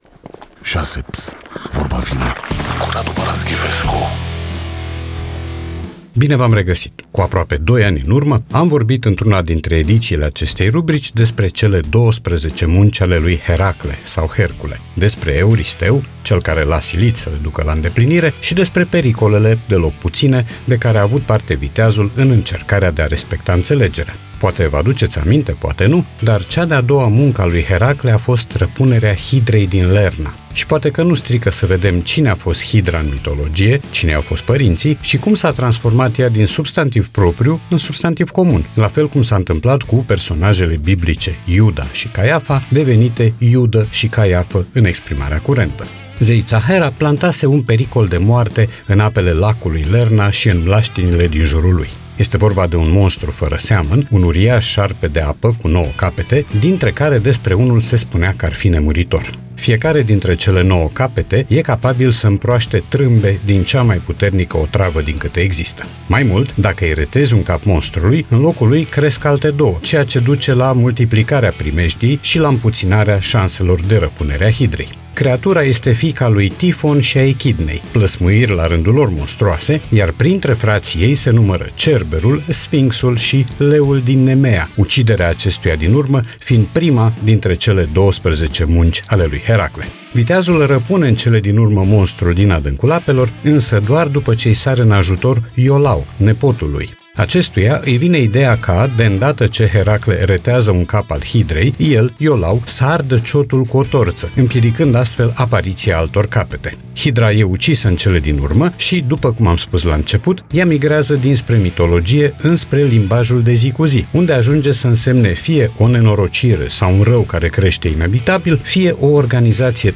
Podcast 25 martie 2025 Vezi podcast Vorba vine, cu Radu Paraschivescu Radu Paraschivescu iti prezinta "Vorba vine", la Rock FM.